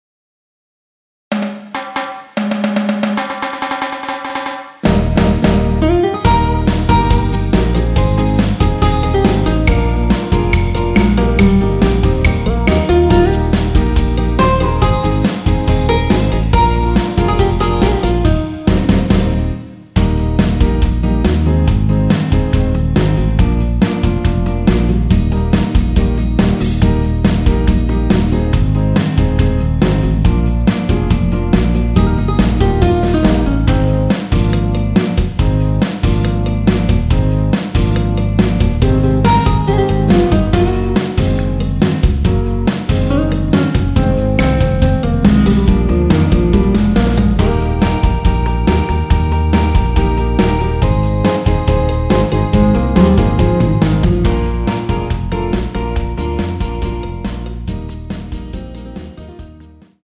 원키에서(-5)내린 남성분이 부르실수 있는 MR입니다.
Eb
앞부분30초, 뒷부분30초씩 편집해서 올려 드리고 있습니다.